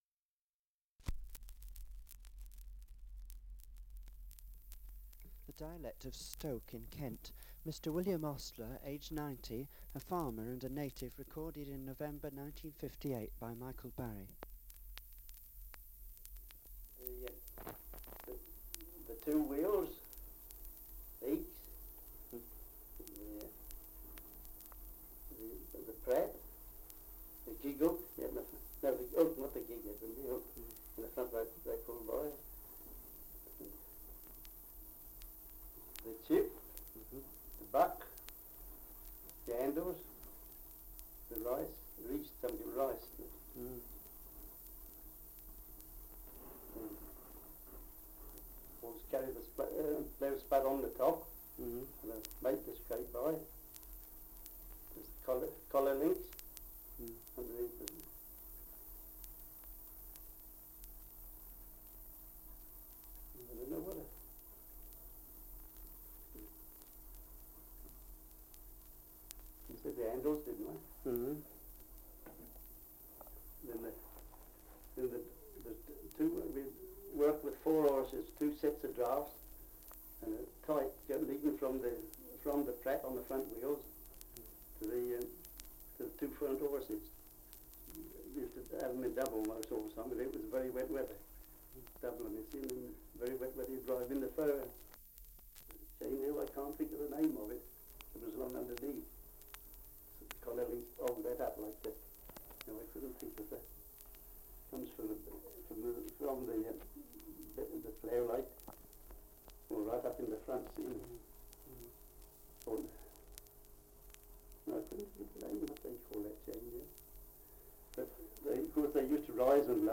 Survey of English Dialects recording in Stoke, Kent
78 r.p.m., cellulose nitrate on aluminium